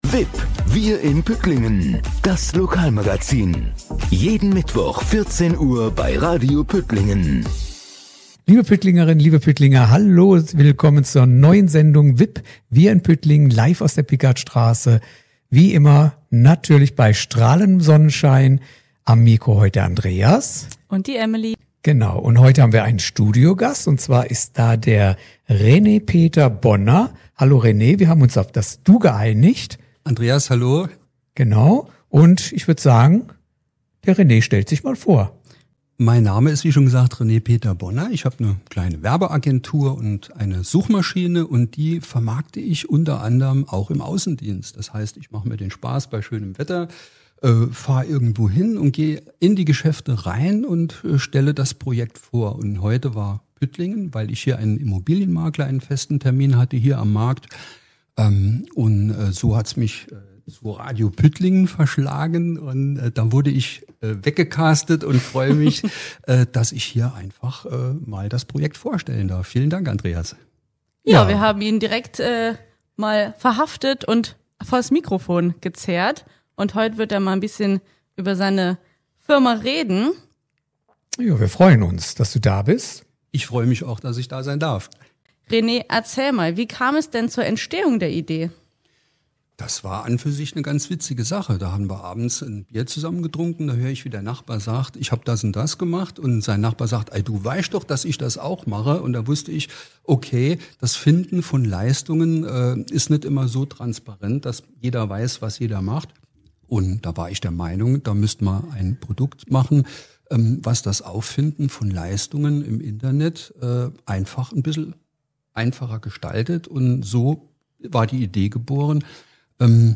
radio_puettlingen2025.mp3